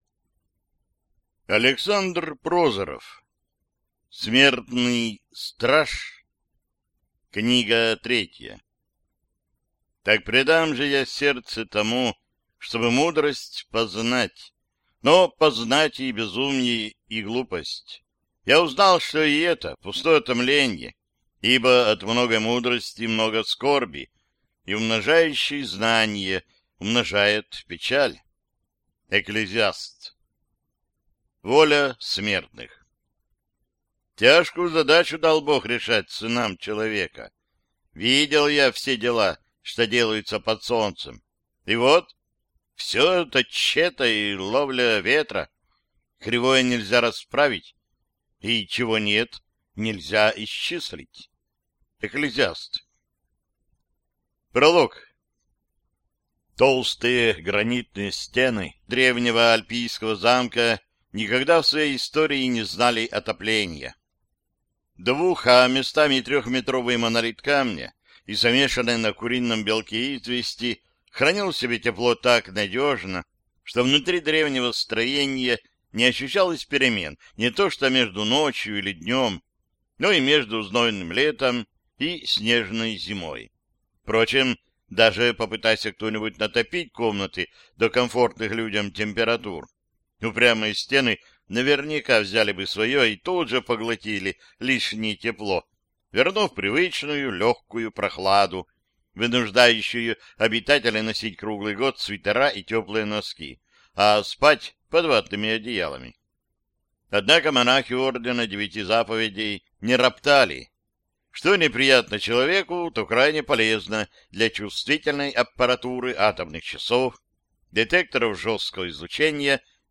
Аудиокнига Воля смертных | Библиотека аудиокниг